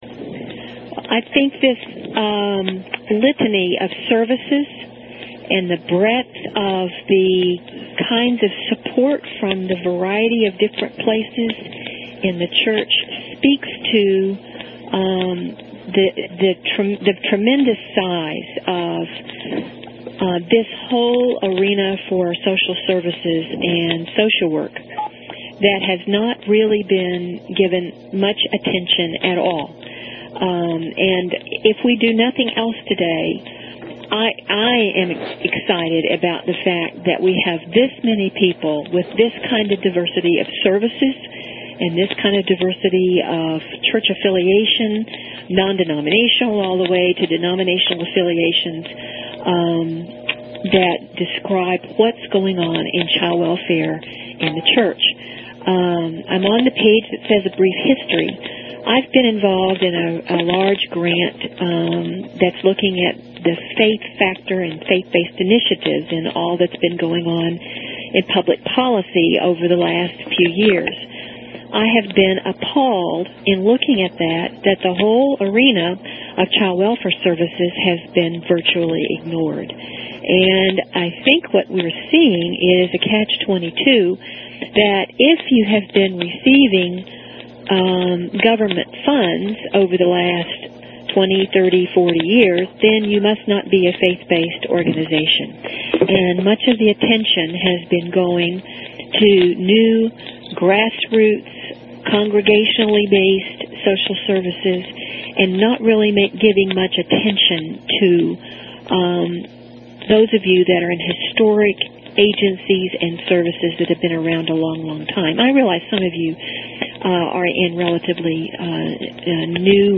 Training format: audio-based